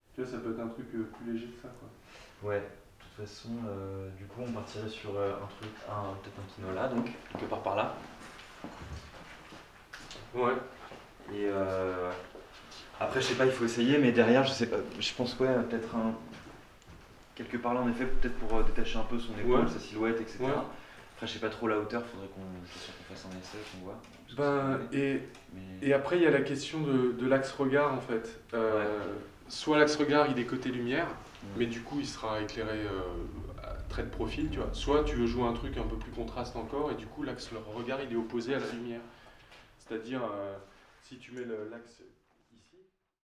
Strasbourg, Neudorf - Nagra Lino